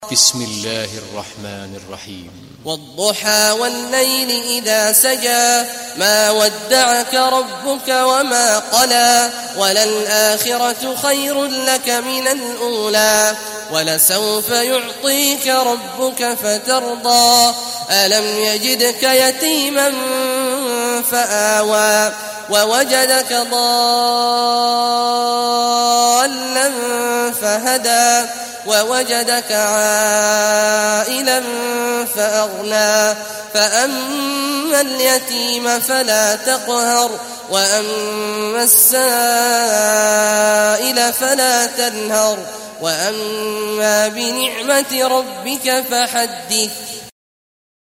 دانلود سوره الضحى mp3 عبد الله عواد الجهني روایت حفص از عاصم, قرآن را دانلود کنید و گوش کن mp3 ، لینک مستقیم کامل